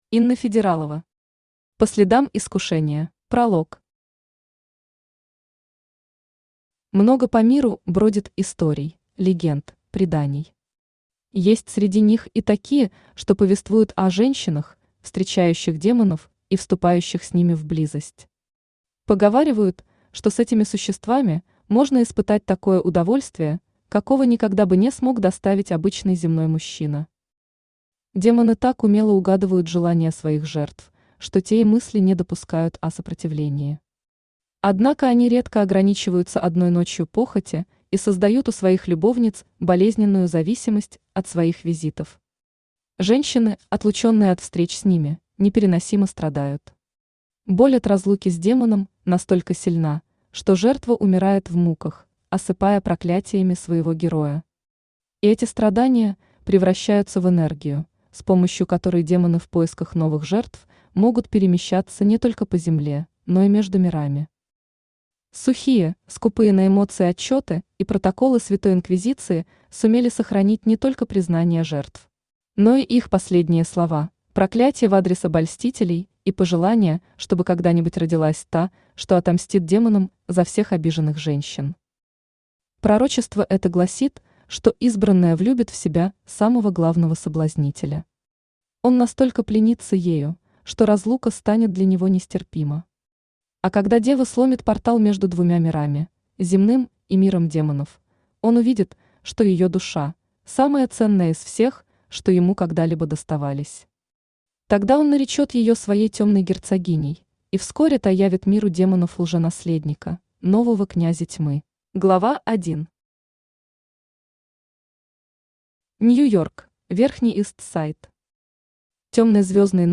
Aудиокнига По следам искушения Автор Инна Федералова Читает аудиокнигу Авточтец ЛитРес.